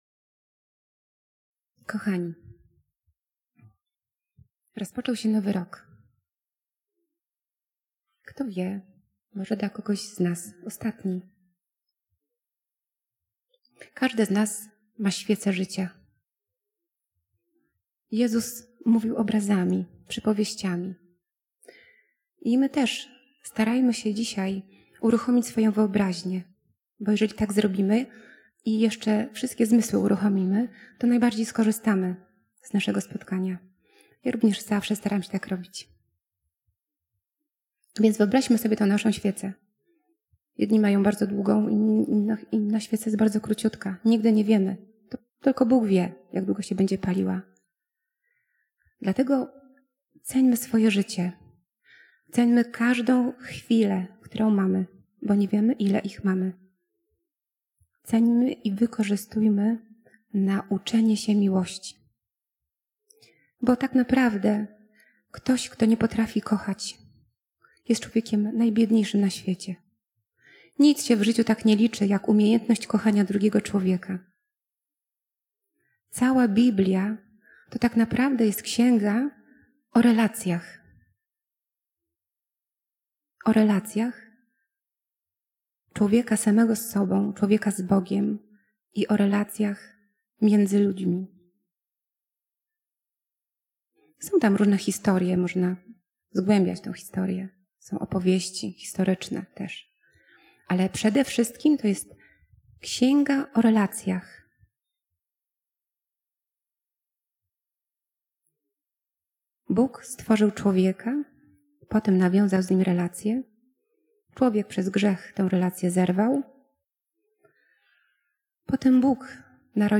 Konferencja lek. med.